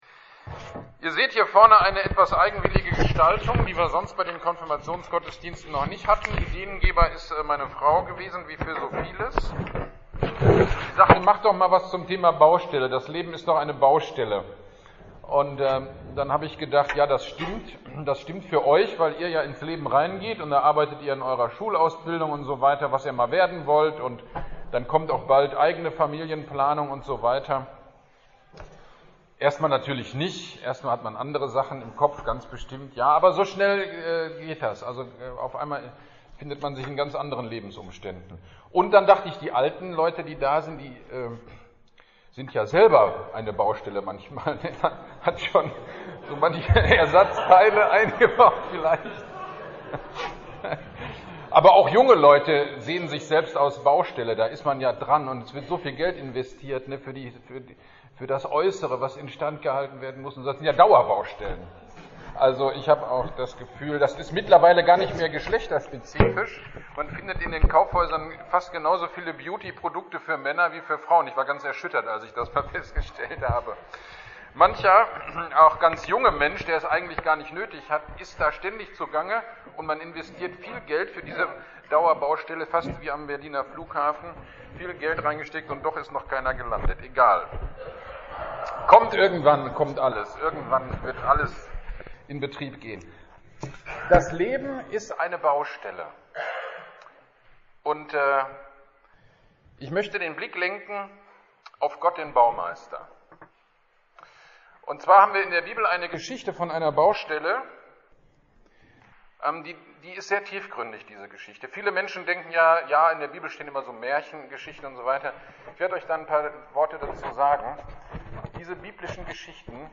Pfingstgottesdienst 2022 Predigt zu 1. Mose 11.1-9 und Apostelgeschichte 2.1-11
Pfingstpredigt-2022.mp3